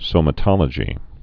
(sōmə-tŏlə-jē)